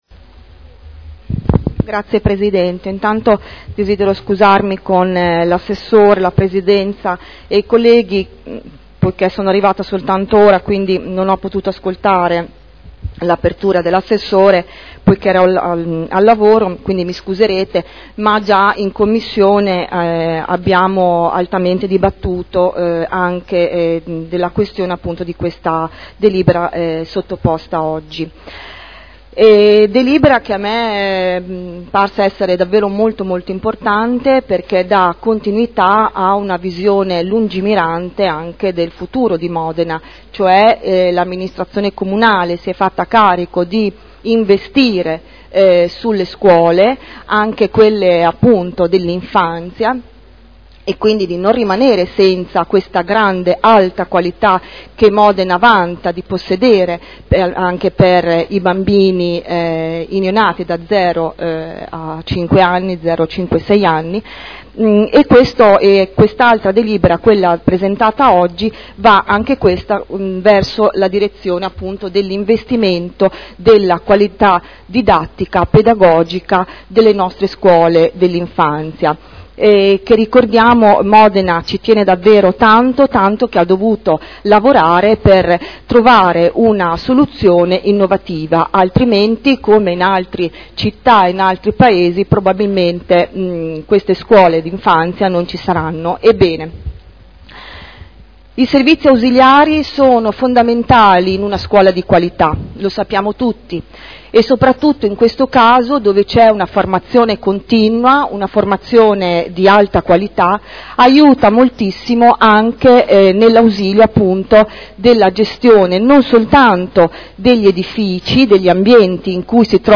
Ingrid Caporioni — Sito Audio Consiglio Comunale
Dibattito.